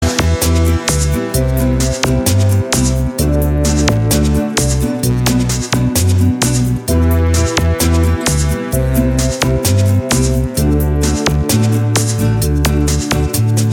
• Качество: 321, Stereo
гитара
спокойные
без слов
Стиль: lounge, chillout